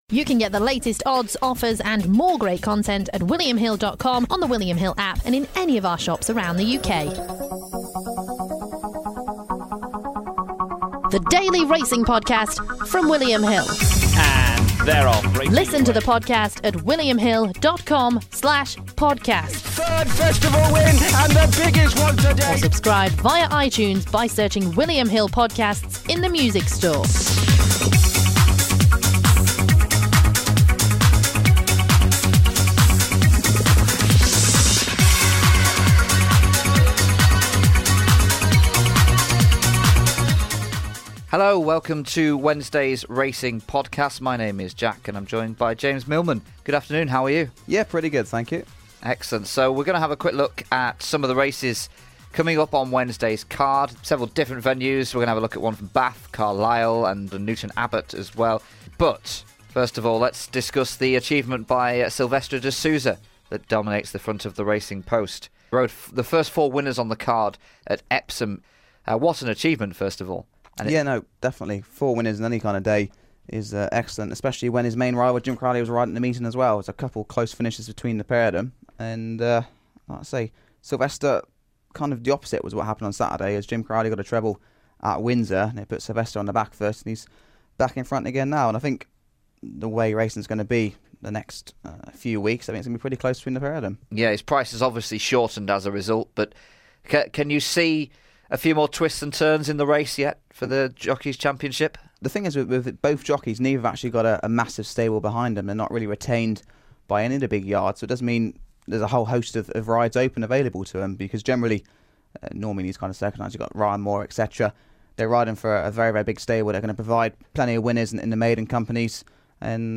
The pair also discuss Silvestre De Sousa's 4-timer & the retirement of David Wachman.